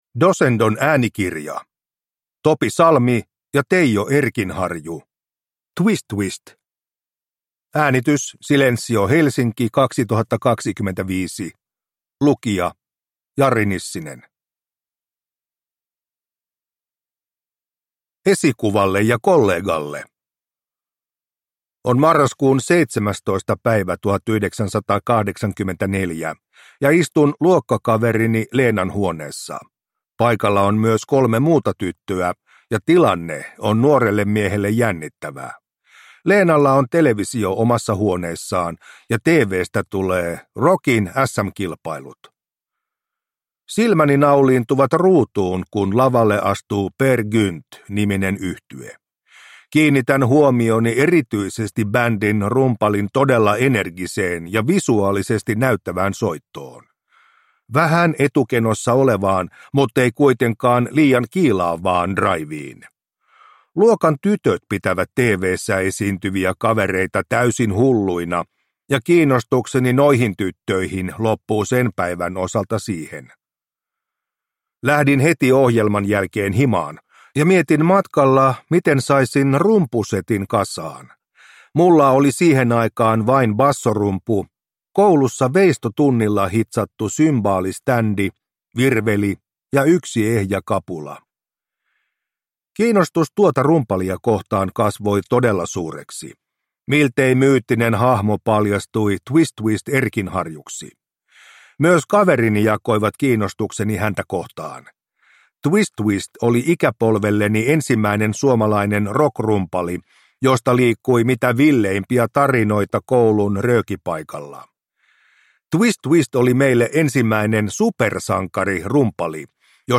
Twist Twist – Ljudbok